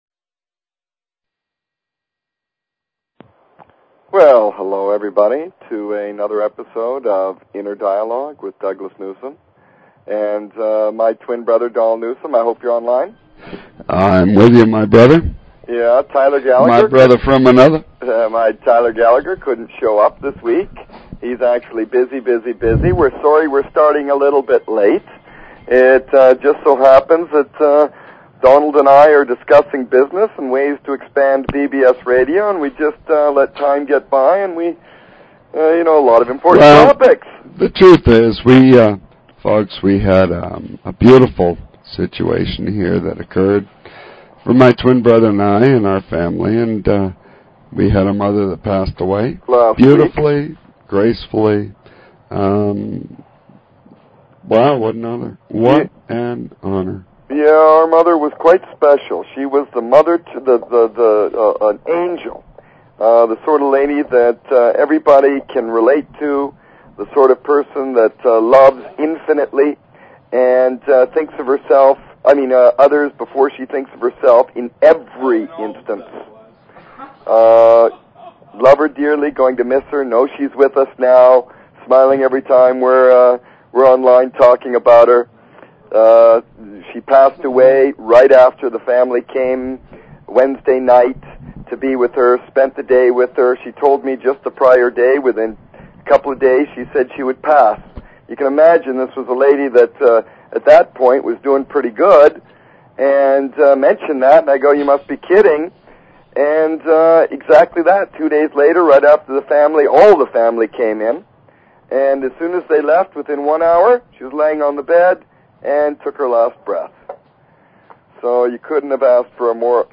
Talk Show Episode, Audio Podcast, Inner_Dialogue and Courtesy of BBS Radio on , show guests , about , categorized as